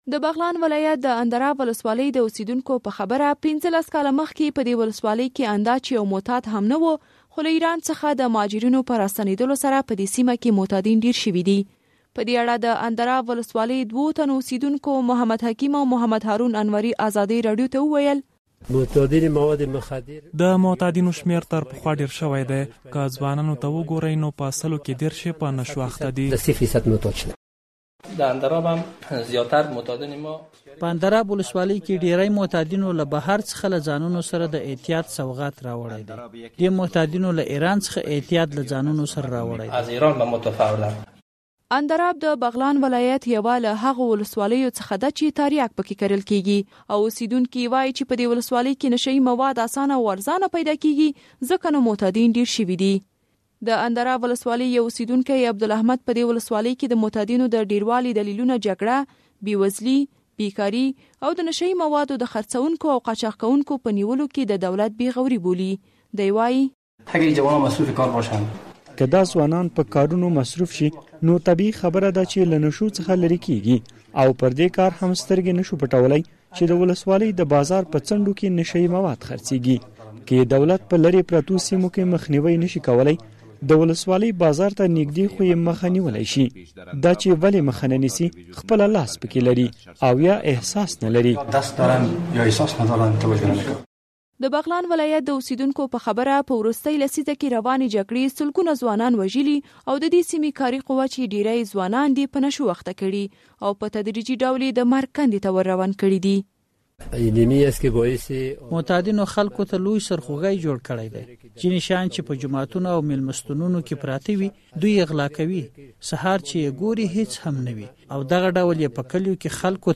د بغلان راپور